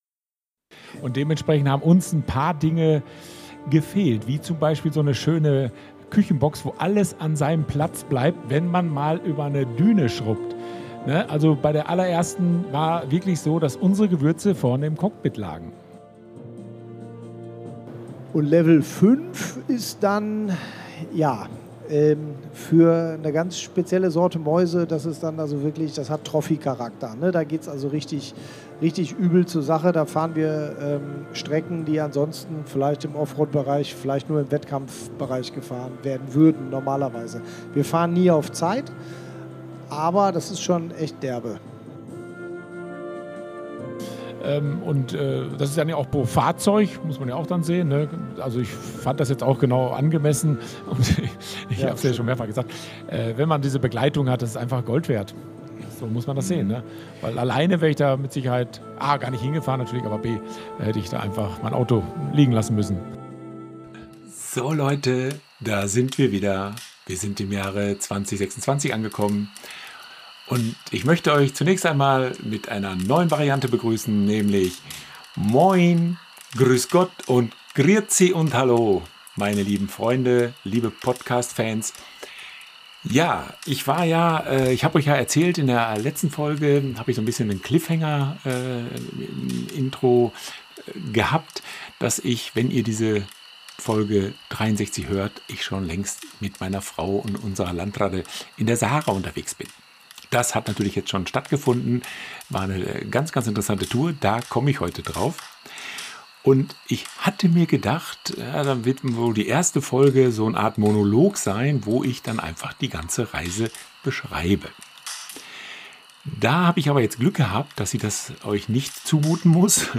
Er wird einen Mitschnitt eines Bühnengesprächs von der Urlaubs- und Freizeitmesse CMT in Stuttgart verwenden.